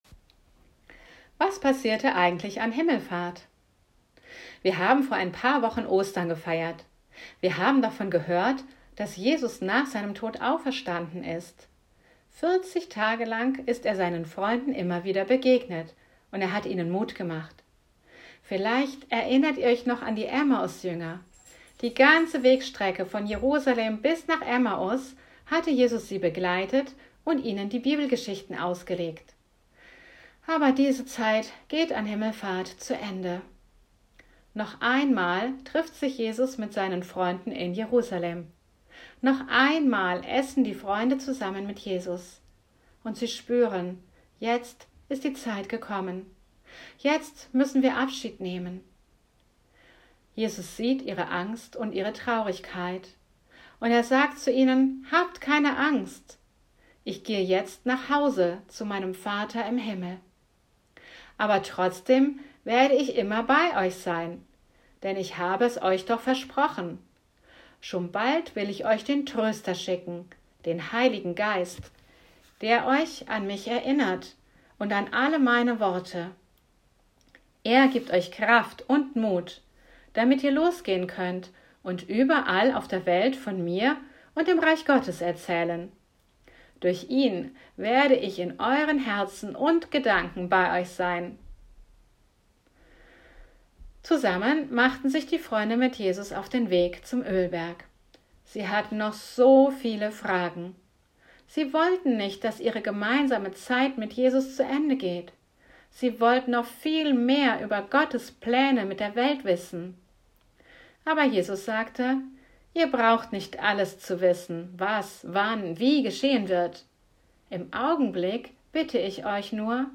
Geschichte & Gebet zum Anhören